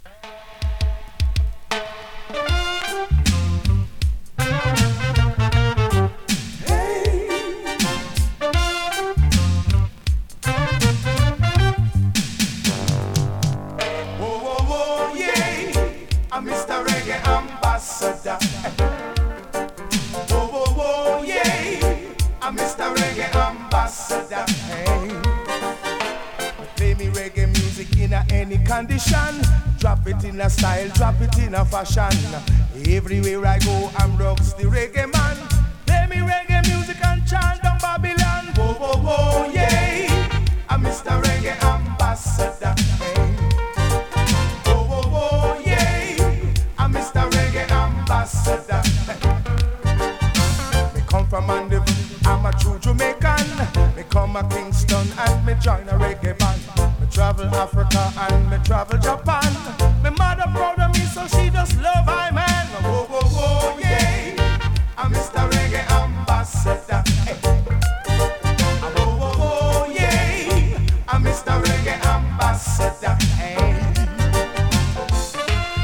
コメントレアJAMAICAプレス!!
スリキズ、ノイズ比較的少なめで